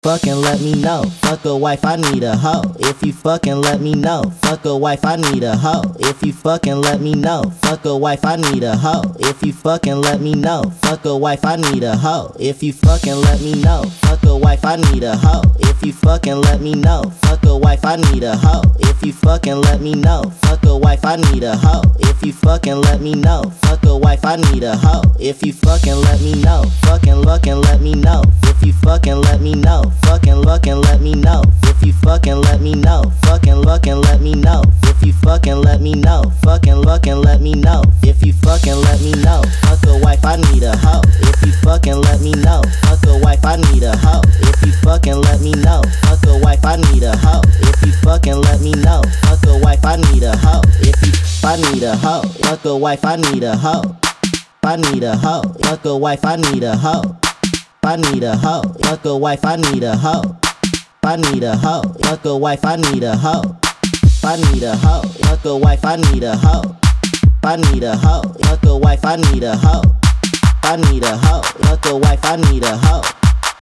Electro House Techno